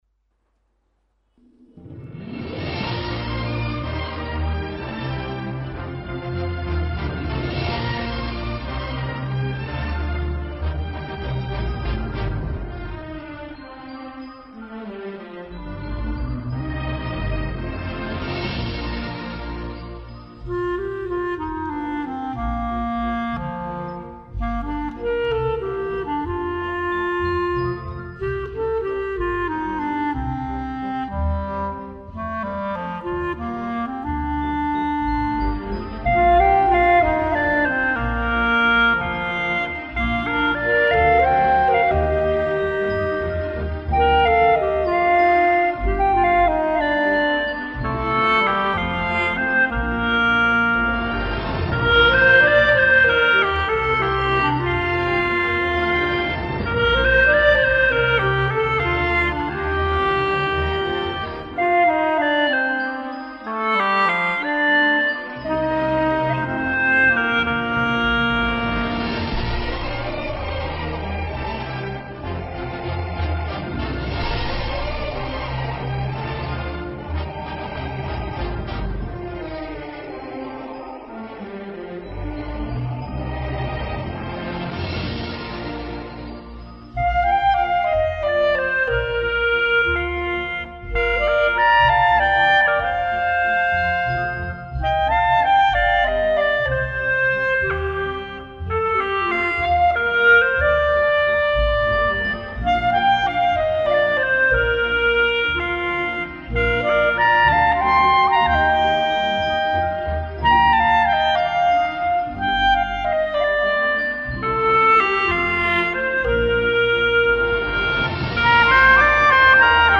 调式 : 降B 曲类 : 红歌